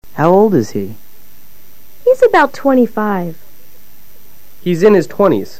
Escucha ahora a los profesores e intenta luego repetir todo simultáneamente.